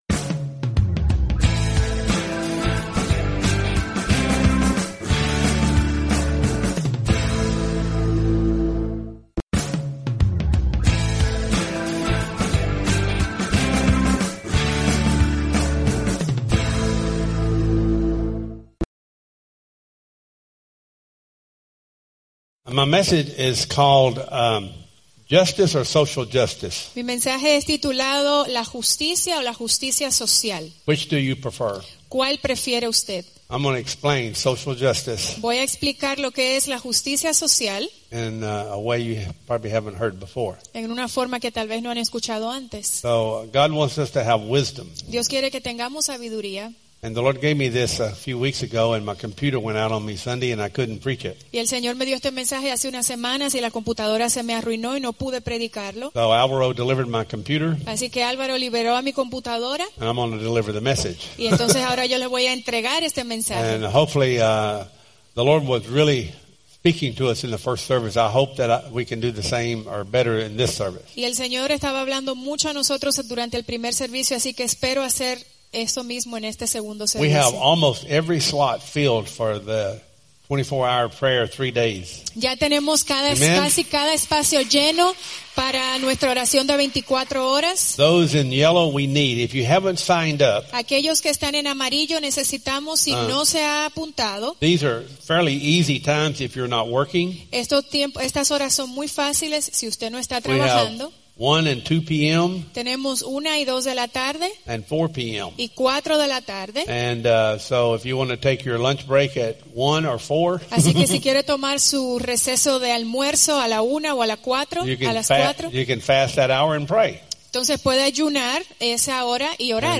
Wisdom for Vision Service Type: Sunday Service « Wisdom for Vision Pt. 21 Justice or Social Justice?